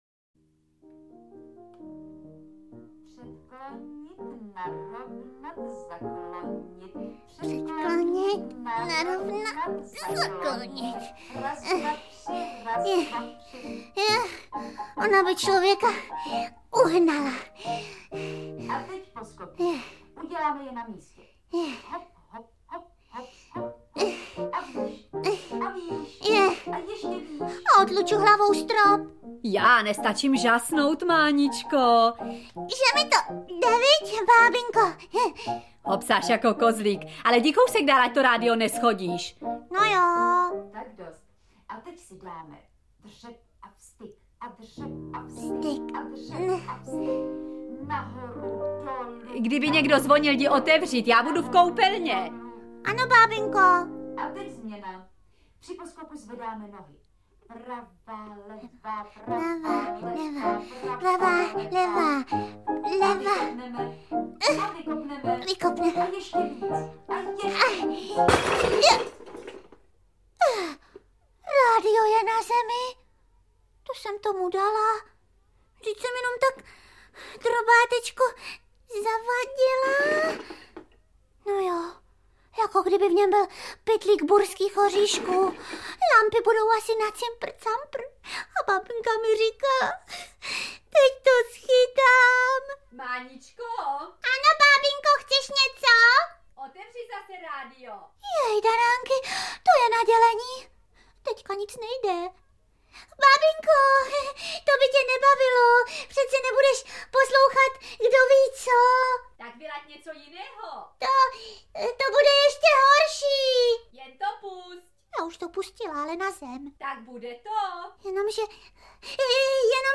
• AudioKniha ke stažení Pohádky s Helenou Štáchovou
Interpret:  Helena Štáchová